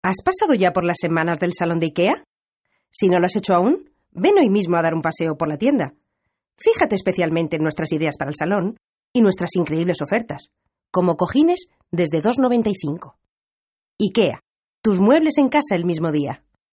Voces Femeninas